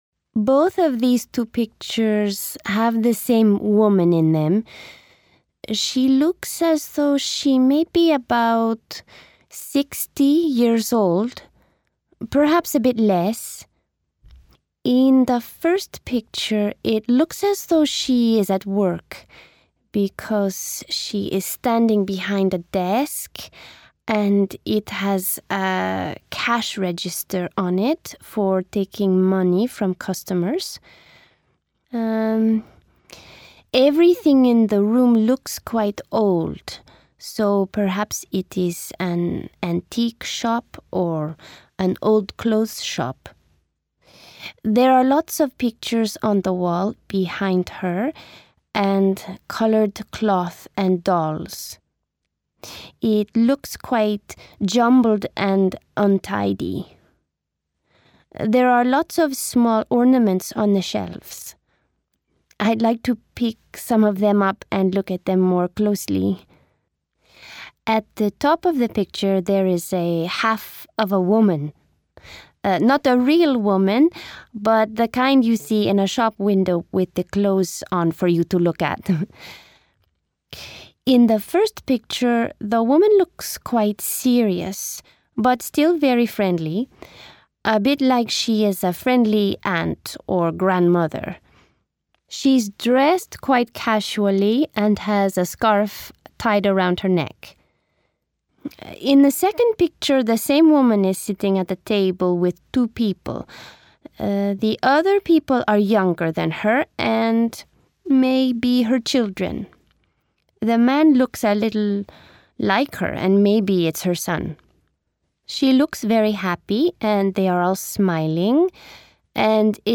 They first listen to a description of two images and then they analyse the language used and try to use it to describe some different pictures.